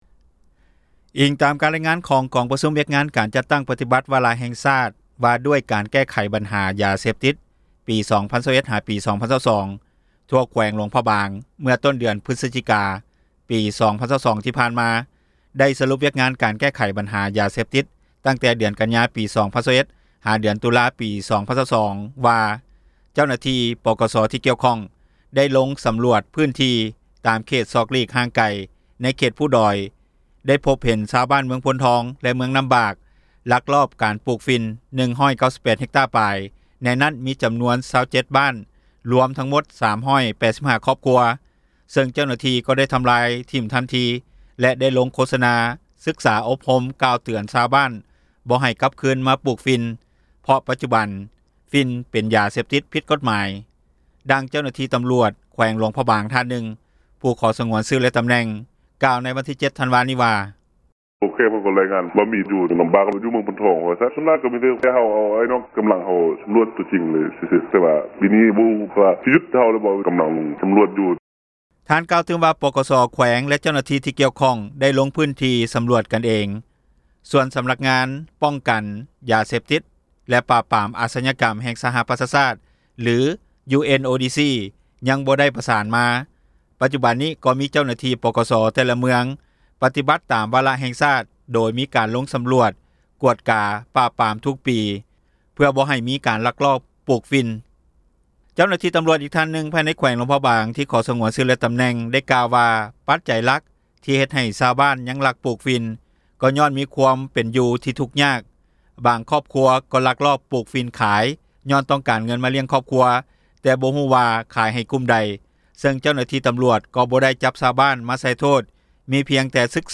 ດັ່ງຊາວບ້ານ ໃນແຂວງຫລວງພຣະບາງ ກ່າວວ່າ: